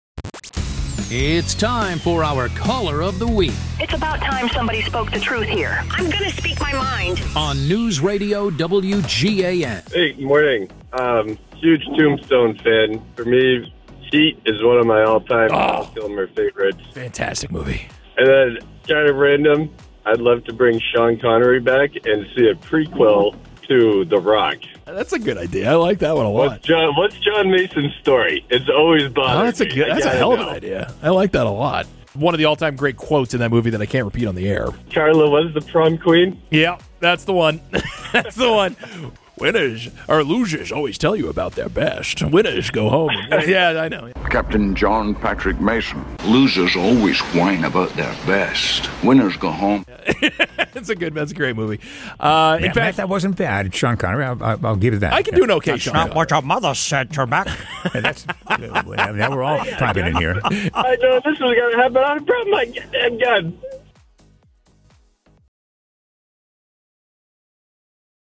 one person calling into the Morning News, who offers an interesting take, a great question or otherwise bring something to the discussion that is missing.